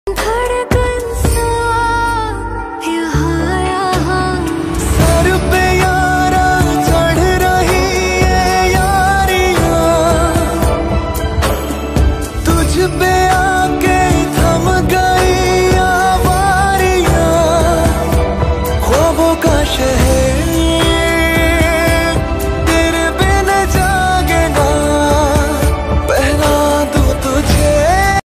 Ringtones Category: Bollywood